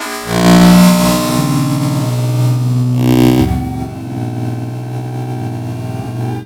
UNDERSEA  -L.wav